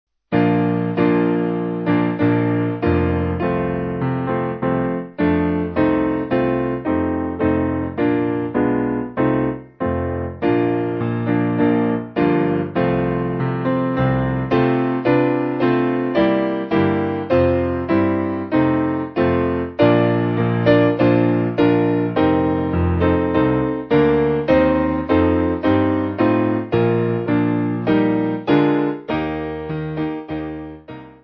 Simple Piano
(CM)   4/Bb